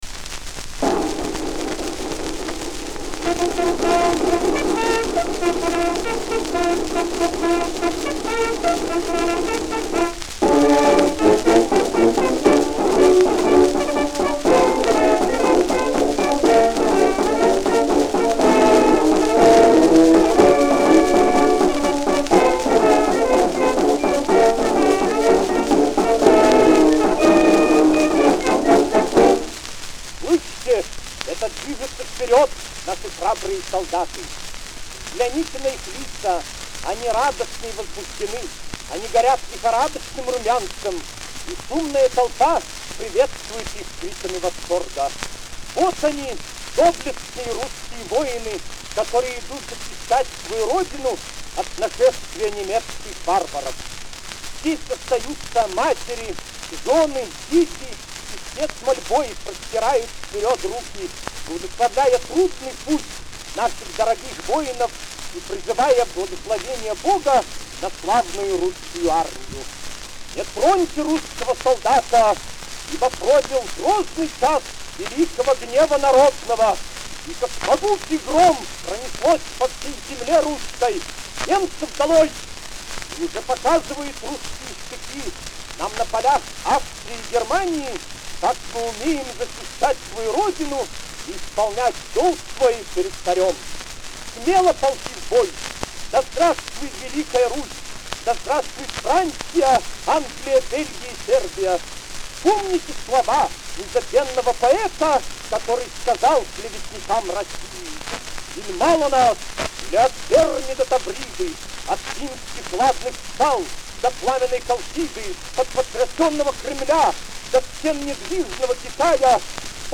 Исполняет: Съ ак. орк. исп.